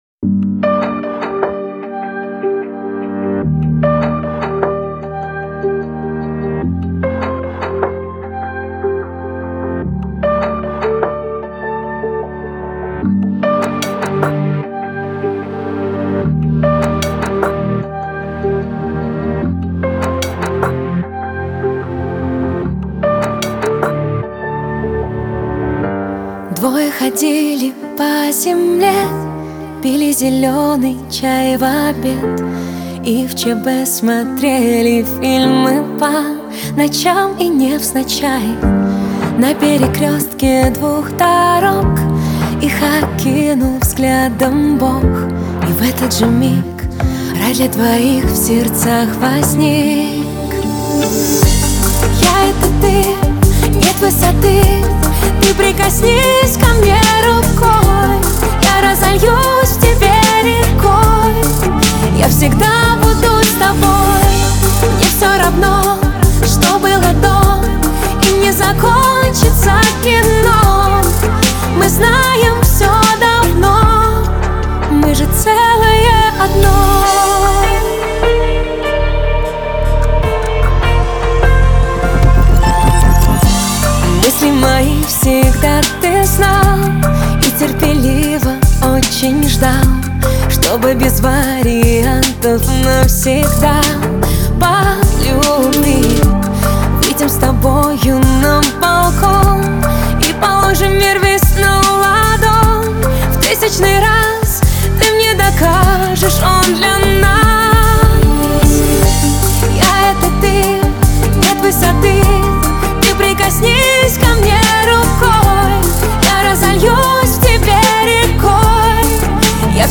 русские медляки
медленные песни